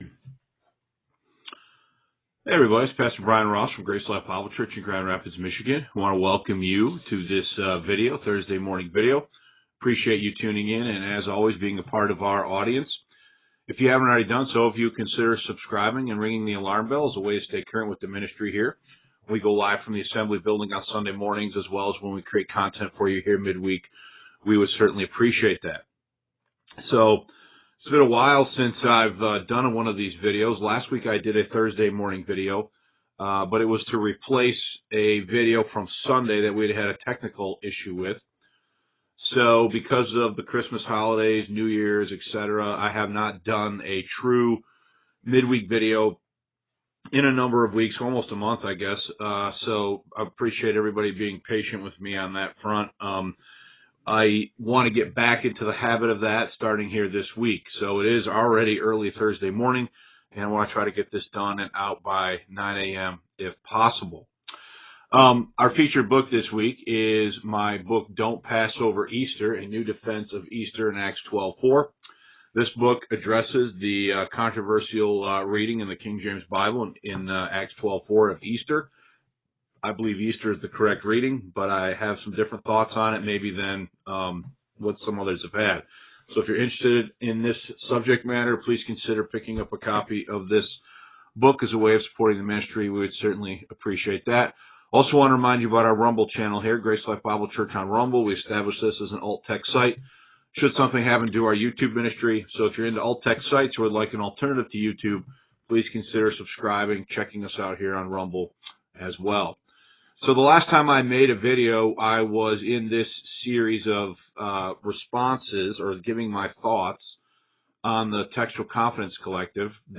Facebook Live Videos (Vlogs) , Mid-Week Messages